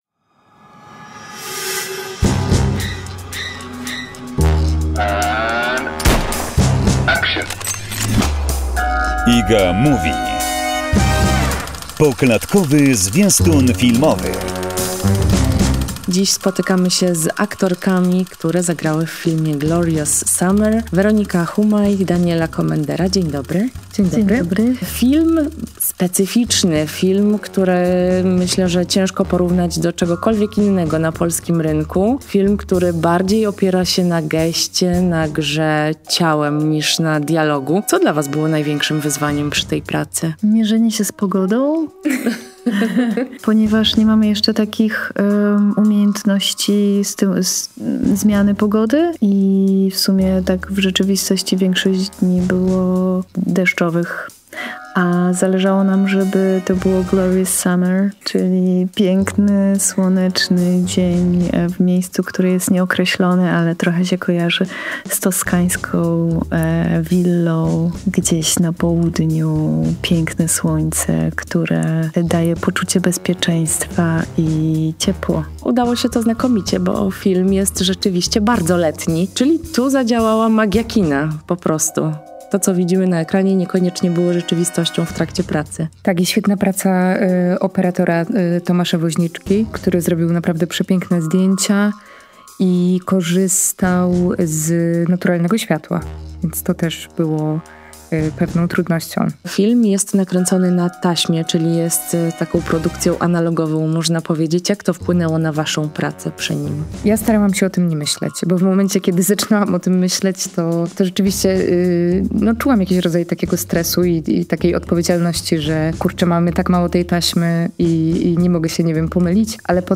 Dziś spotykamy się z aktorkami, które zagrały w filmie „Glorious summer”